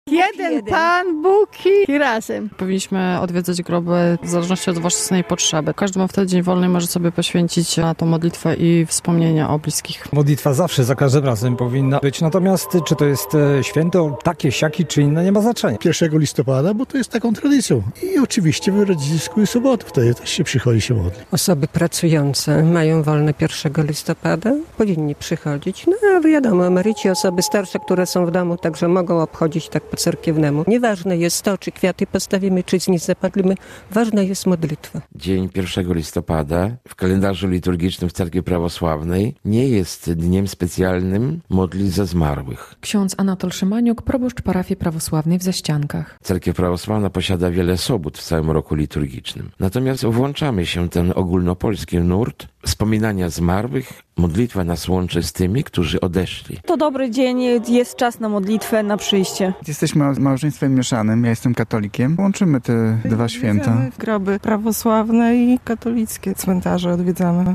Nie ważne jest to, czy kwiaty postawimy, czy znicz zapalimy, ważna jest modlitwa - mówi jedna z kobiet odwiedzających cmentarz.
Jesteśmy małżeństwem mieszanym i łączymy te dwa święta, groby prawosławne i katolickie cmentarze odwiedzamy - mówi mężczyzna napotkany na cmentarzu.